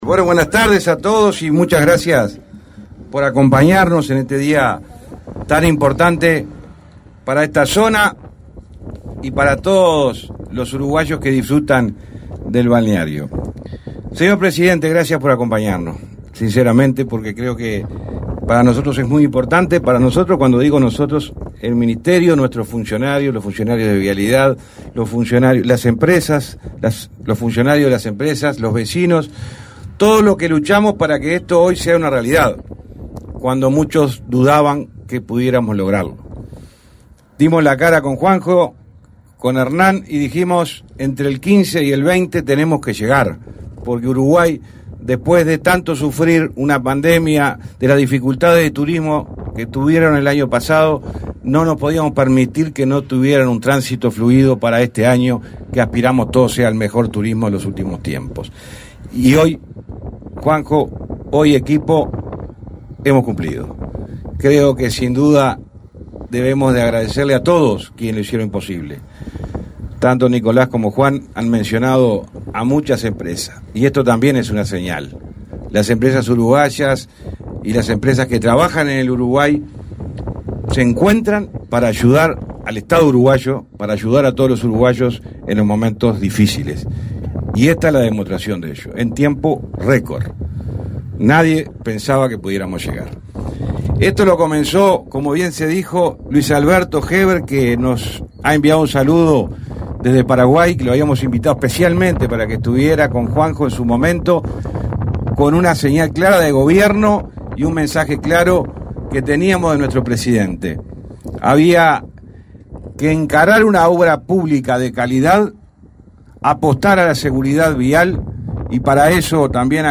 Conferencia de prensa por la inauguración de la estación de energía eléctrica en Punta del Este